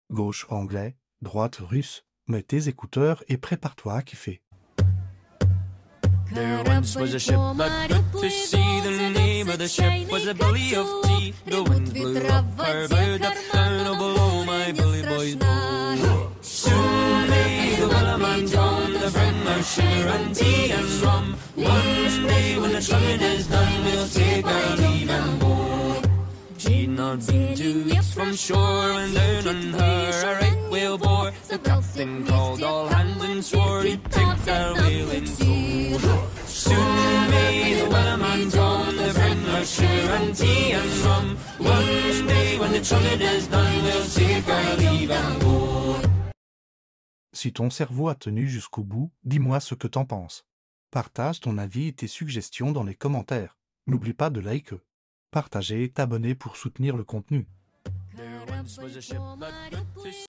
Une seule musique, deux langues.